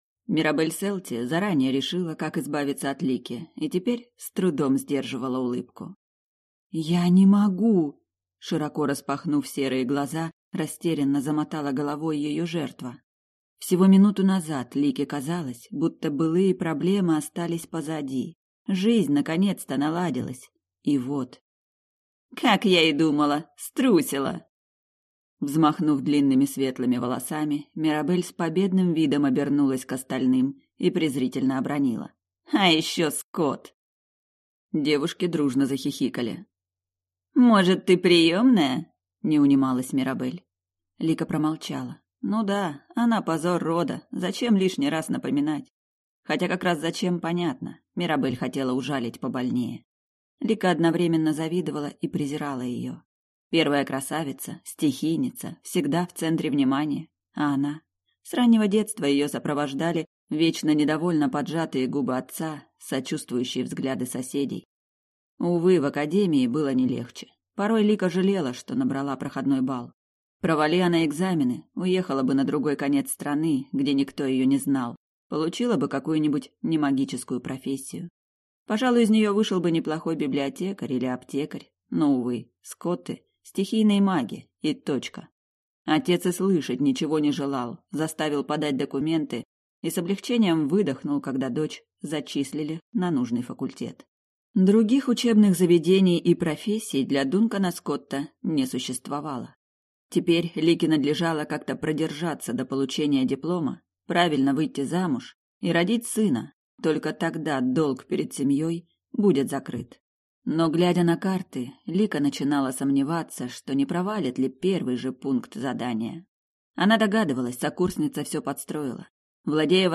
Аудиокнига Магистр темных дел | Библиотека аудиокниг